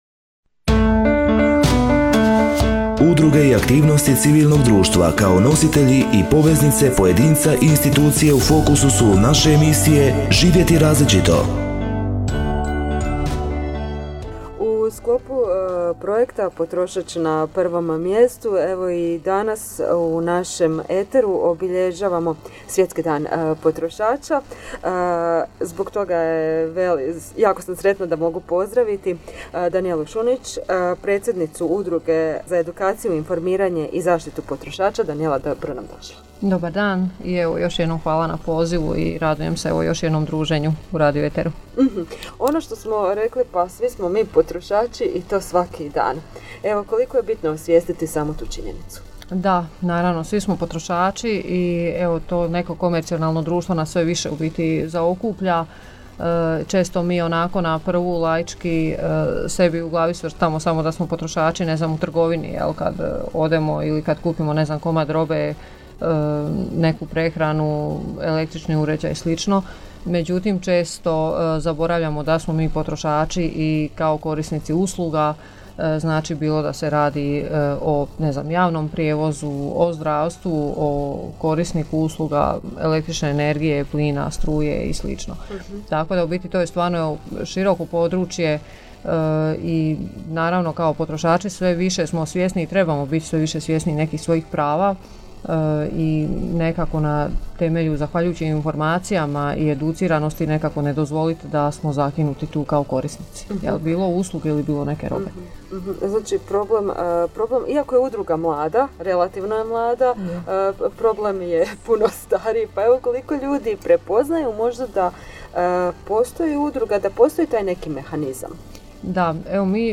zaštitu i informiranje potrošača e-ZIP gostuje na Radio92FM i informira građane o njihovim potrošačkim pravima.
reportažu